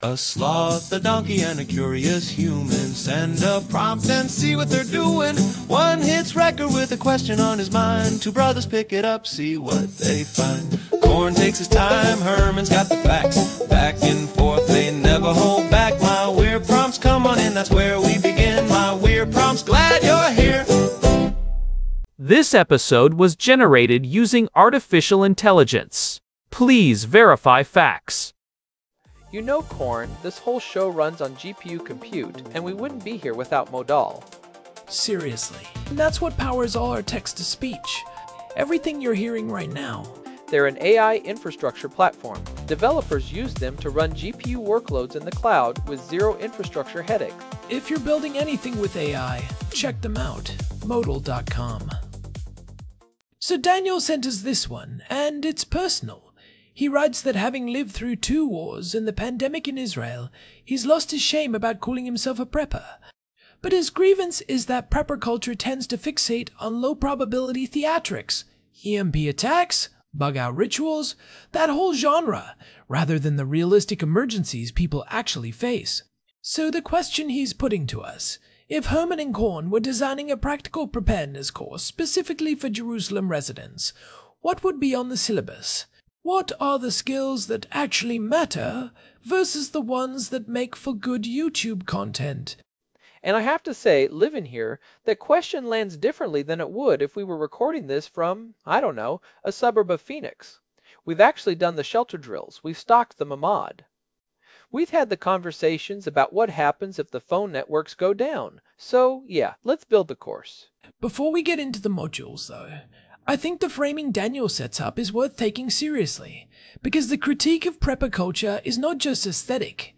Forget the faraday cages. Two hosts design a real emergency syllabus for a city that's lived through actual crises.
AI-Generated Content: This podcast is created using AI personas.